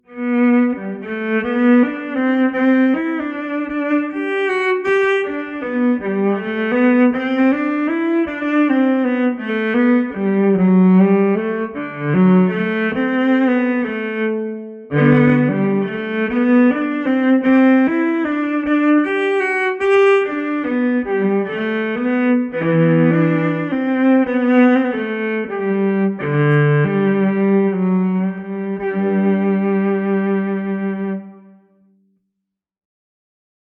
This is an electric violin (Yamah YEV-104) with Octave strings, so it plays an octave lower than a regular violin. Some fiddle players call it a Chin Cello.
AltiSpace Reverb - to give more of a room/chamber effect
Thafknar - I use a Cello IR (From 3 Sigma Audio) to improve the tone of the piezo pickup FabFilter EQ - to well, EQ it - I take out the very low bottom end, and very high top end and a slight notch a couple places